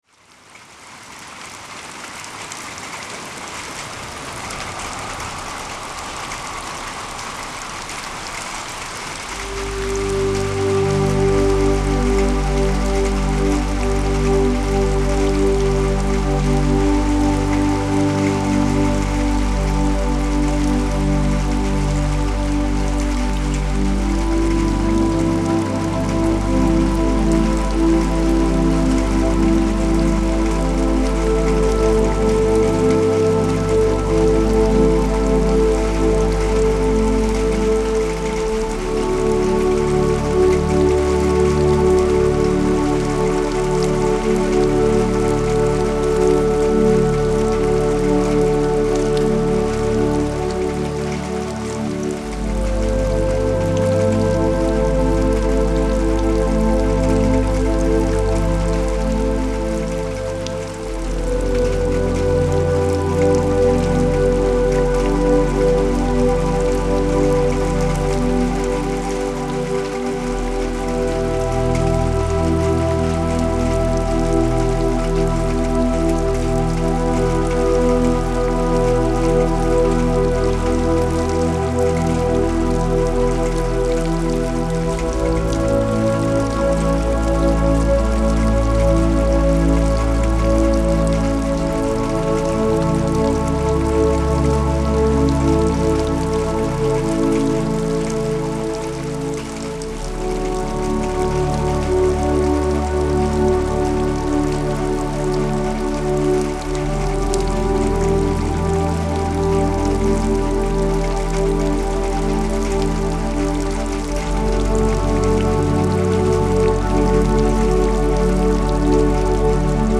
which is a combination of relaxing rain sounds with the song